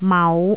聽得出來嗎？每一行的詞語都是同一字調，第一行的是第1調，而第二行的是第4調；第1調是最高音的調，而第4調則是最低音的調。